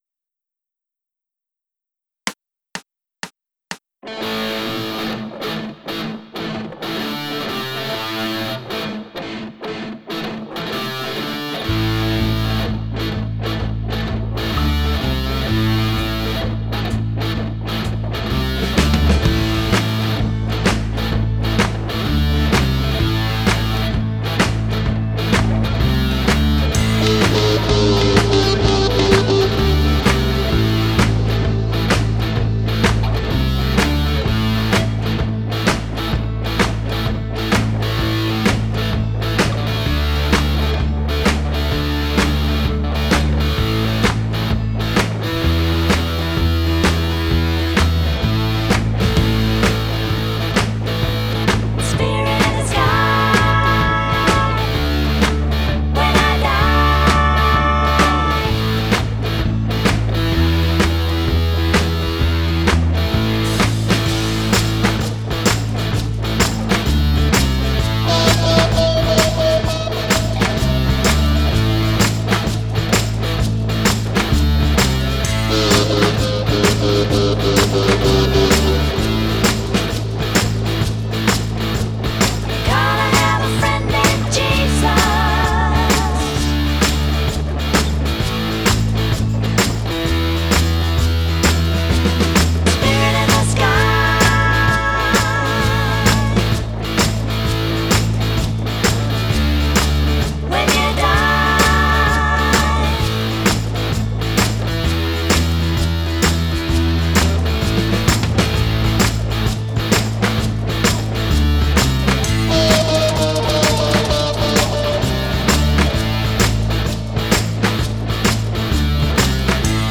Performance Tracks